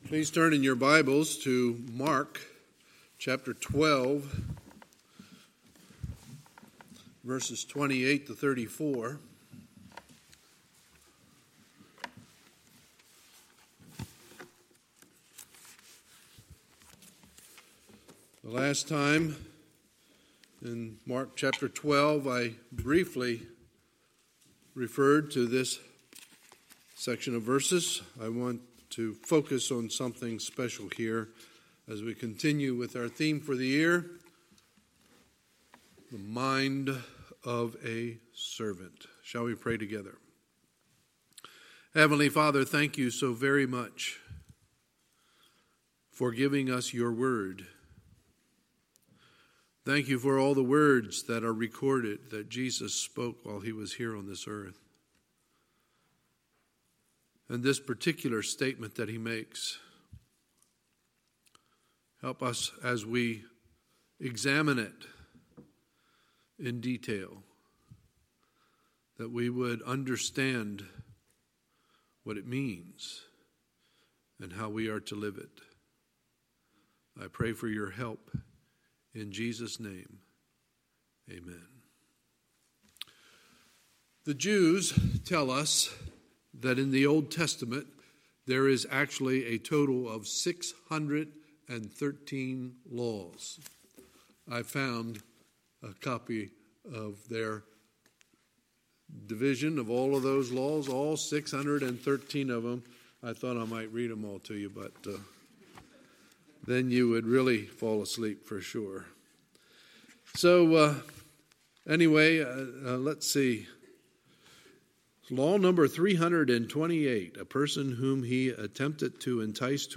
Sunday, October 6, 2019 – Sunday Morning Service
Sermons